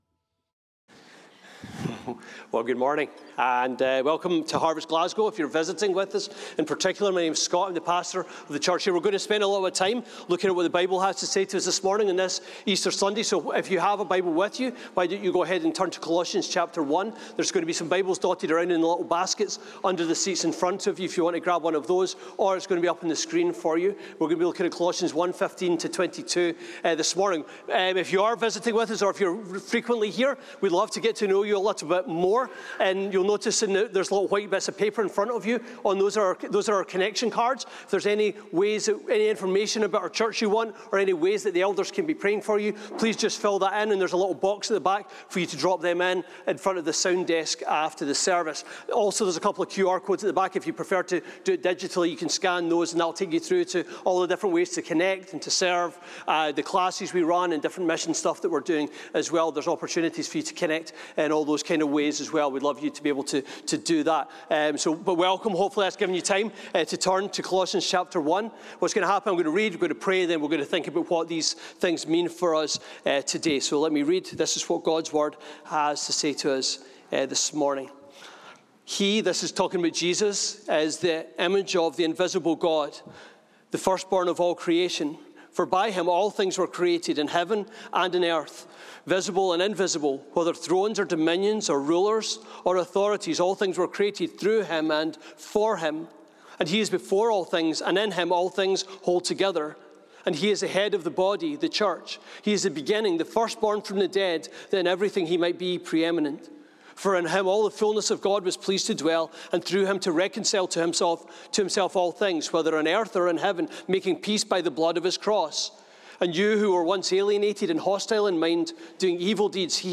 This sermon is also available to watch on YouTube.